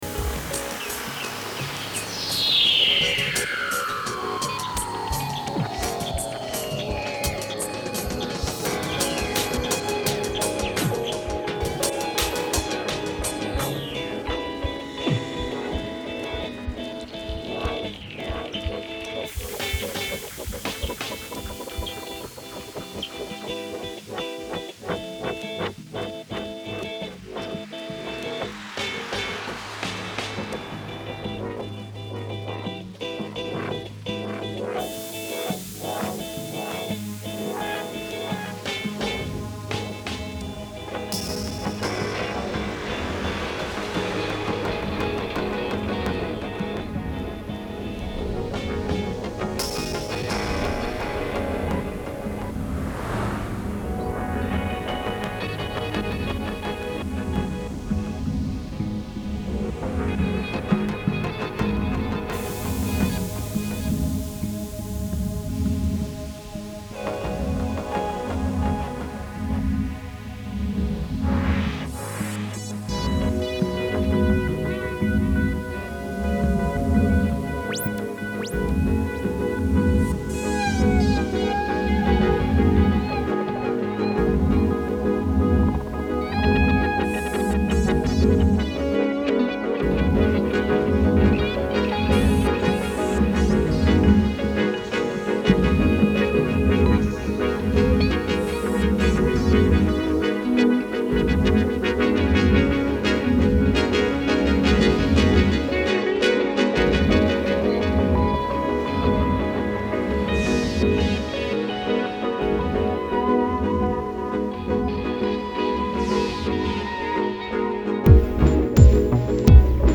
electronic Ambient الکترونیک Electronic rock Instrumental